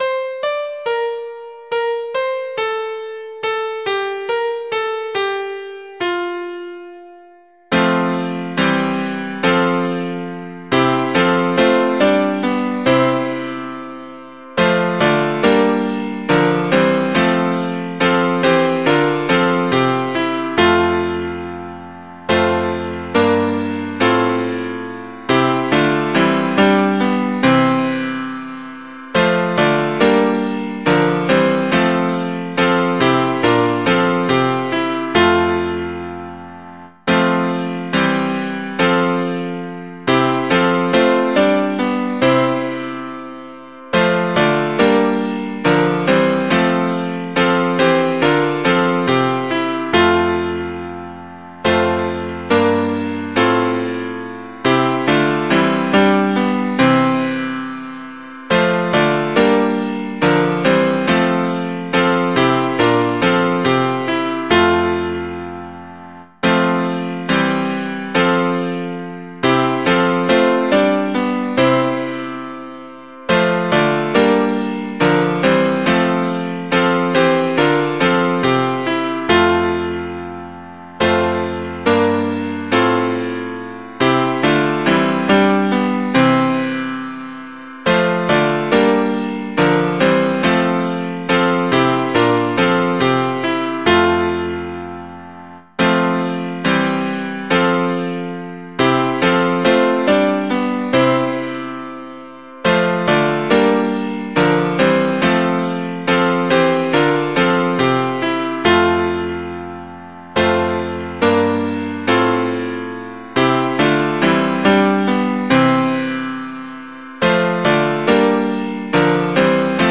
Music: 'Ebeling' or 'Bonn' or 'Warum Sollt Ich' or 'All My Heart This Night' Johann Georg Ebeling, 1666.
Setting: Karl Brauer, 1906, alt.
Mp3 Audio of Tune Abc source